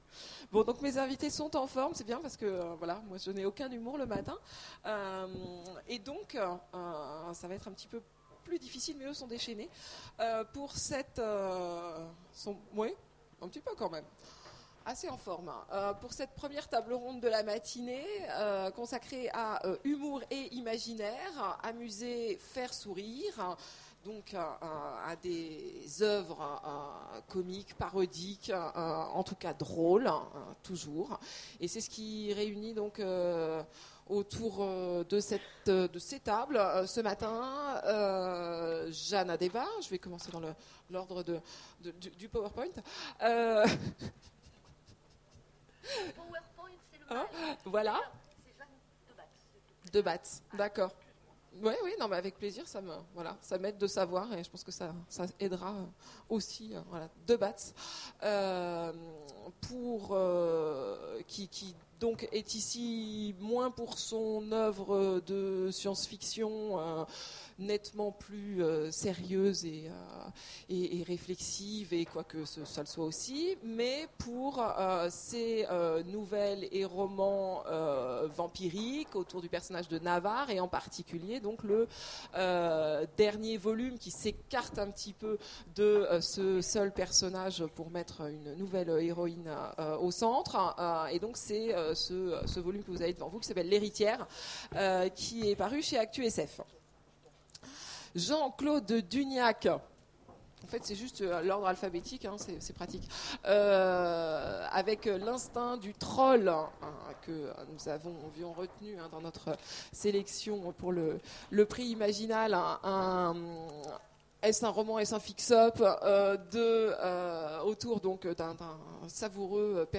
Imaginales 2015 : Conférence Humour et imaginaire